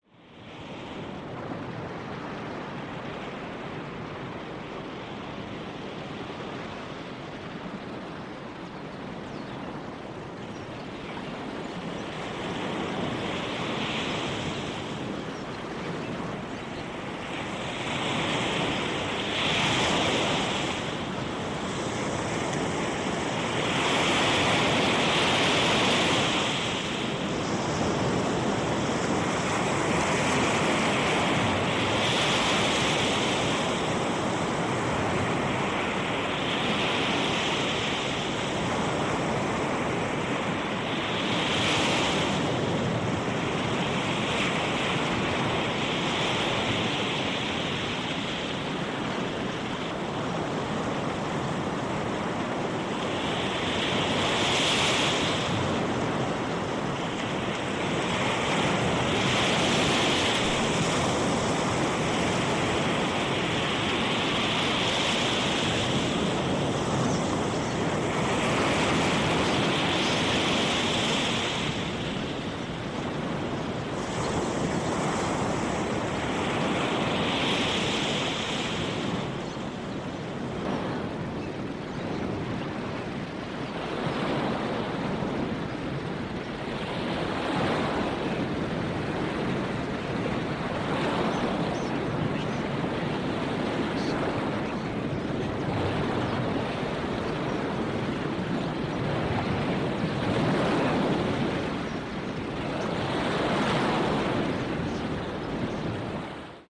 NATURE
available with music or nature soundtrack
InnerTalk-End-Co-Dependent-Patterns-NATURE-SAMPLE-.mp3